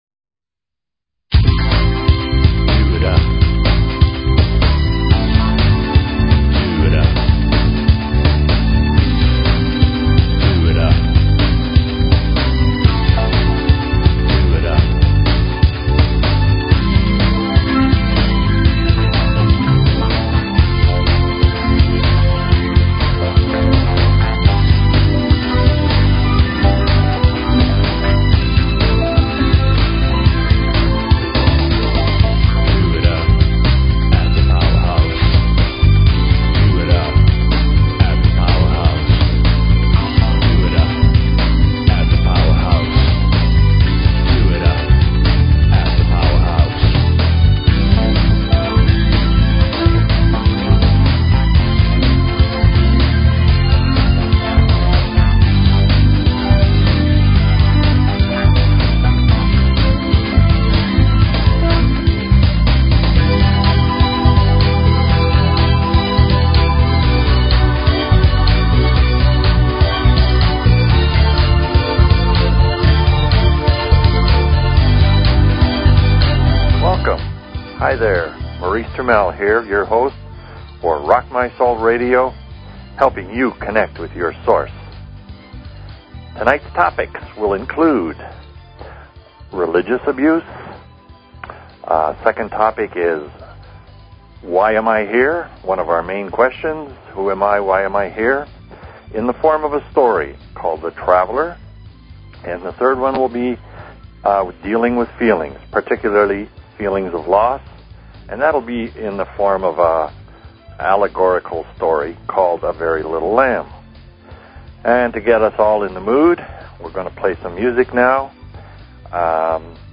Talk Show Episode, Audio Podcast, Rock_My_Soul_Radio and Courtesy of BBS Radio on , show guests , about , categorized as